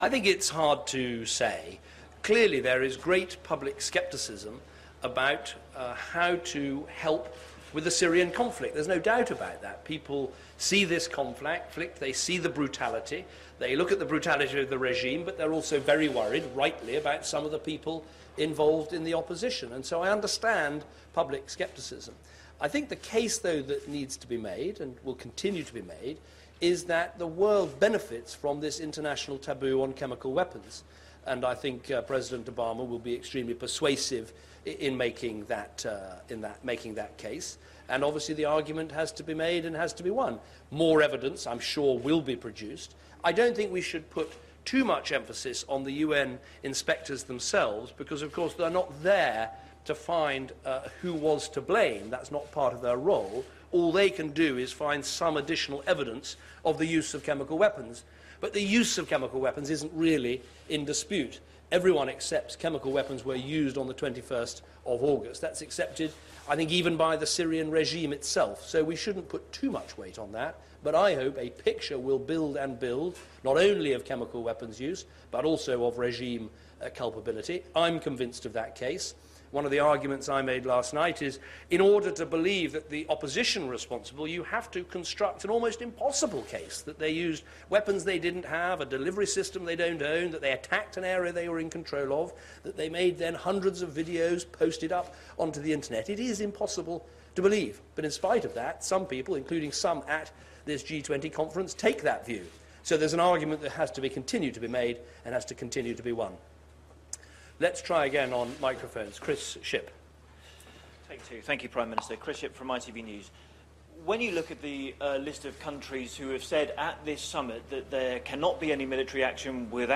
David Cameron takes questions at the G20